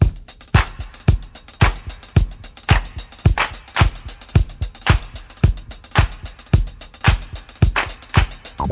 • 110 Bpm Drum Groove F Key.wav
Free drum loop sample - kick tuned to the F note. Loudest frequency: 2039Hz
110-bpm-drum-groove-f-key-uS2.wav